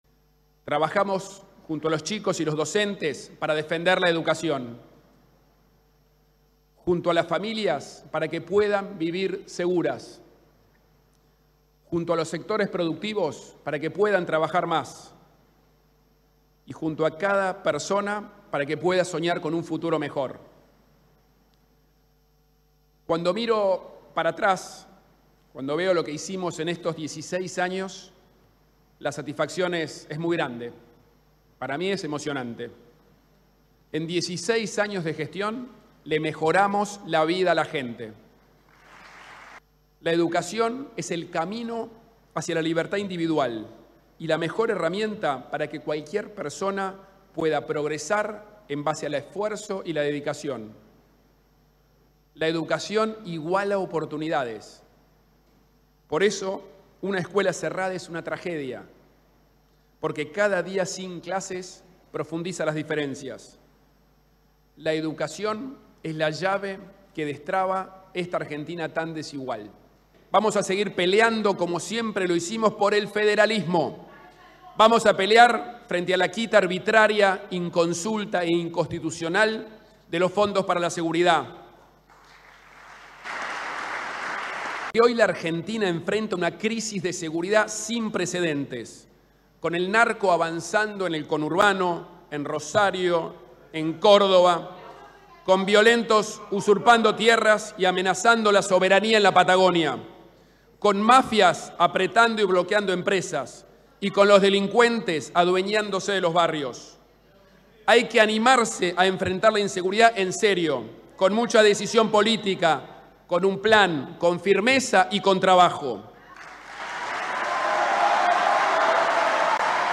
El jefe de Gobierno de la Ciudad de Buenos Aires, Horacio Rodríguez Larreta, inauguró el nuevo período de sesiones ordinarias de la Legislatura porteña, con un discurso en el que hizo un repaso de su gestión, con foco en la educación, la seguridad y el trabajo, e hizo referencia al futuro: “Con trabajo y con esfuerzo se puede vivir mejor; lo vamos a lograr en todo el país”.
Escuchá el discurso de Horacio Rodríguez Larreta.
Rodriguez-Larreta-inaugura-sesiones.mp3